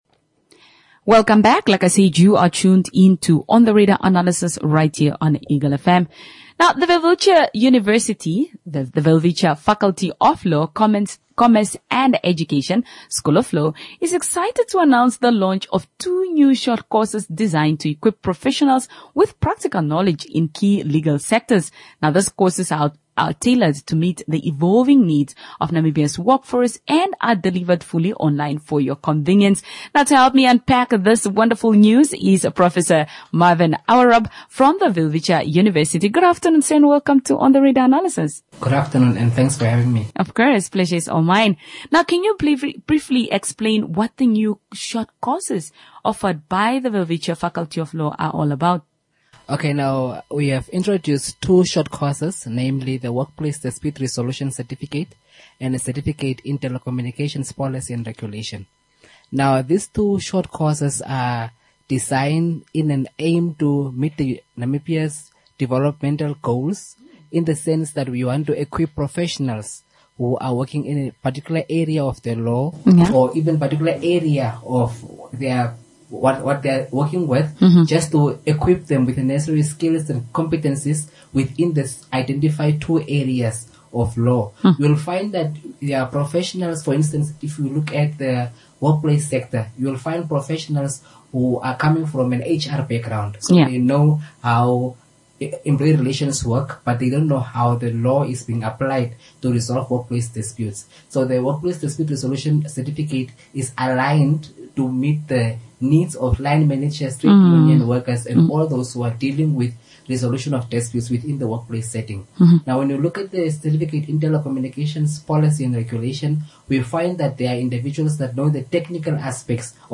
WELWITCHIA NEW COURSE INTERVIEW (13 AUGUST 2025)